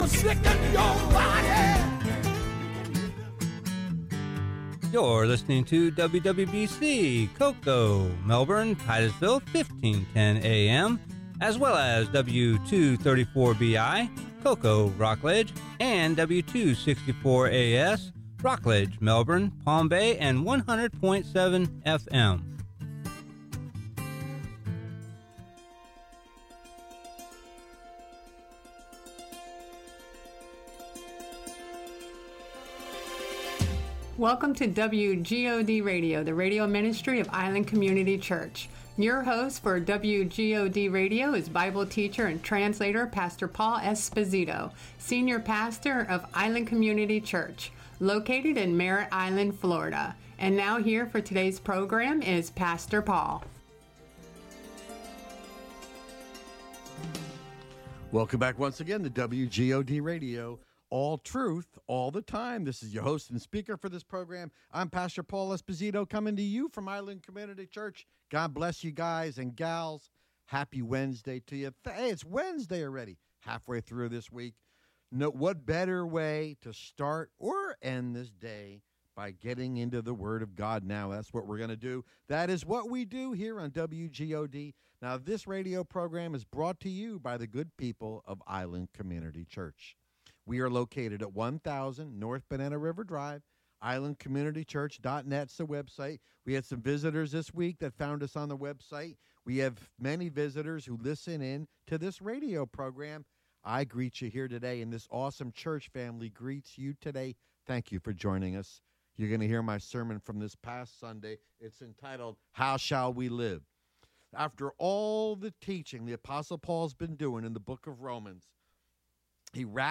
Sermon "How Shall We Live?